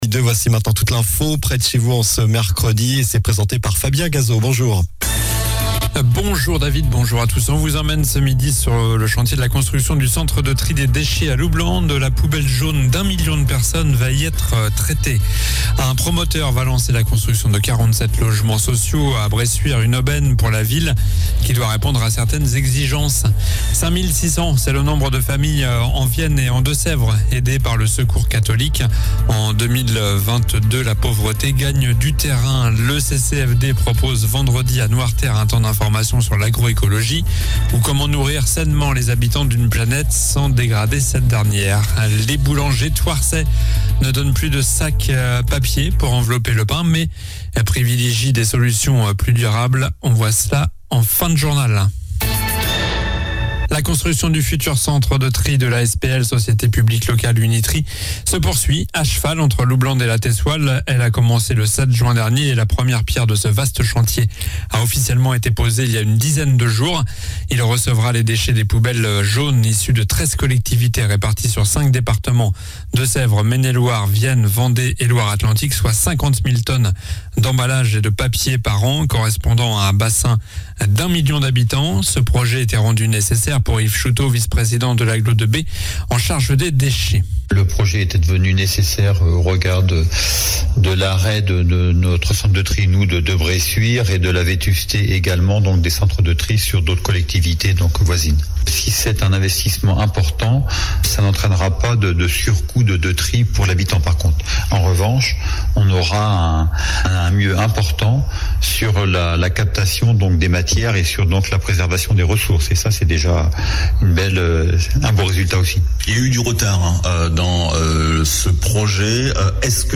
Journal du mercredi 22 novembre (midi)